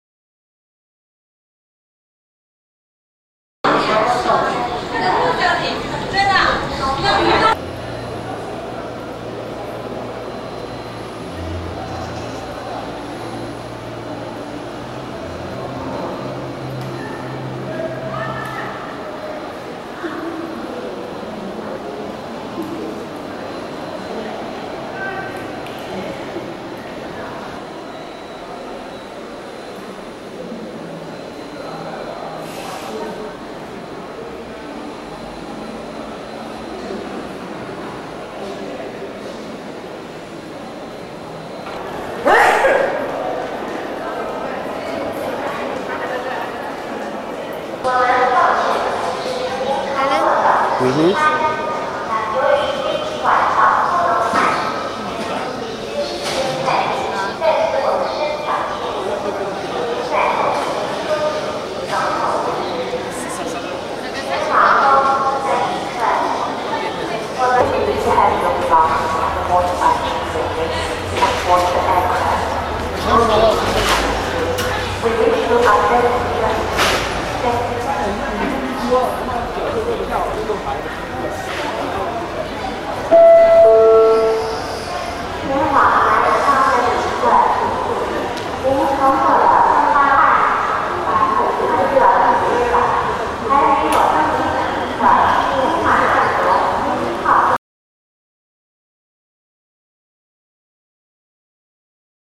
129. Arriving at Kunming Airport